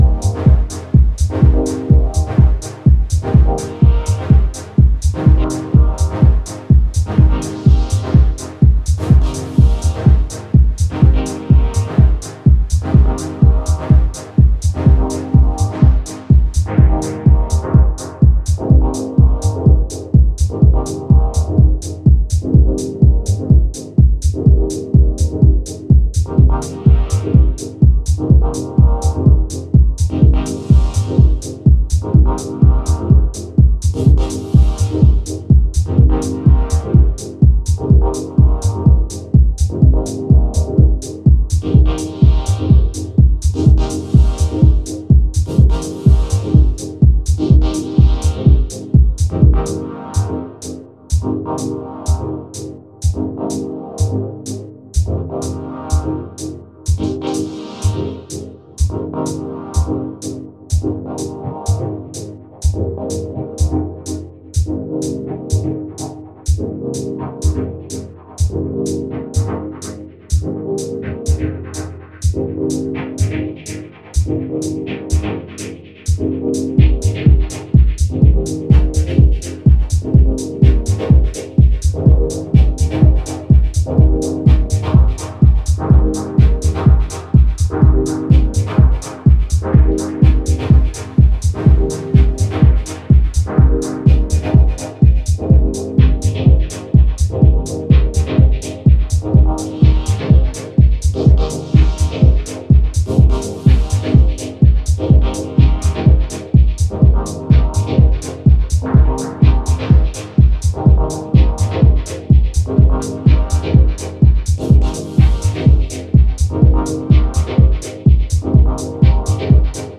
true analog dub techno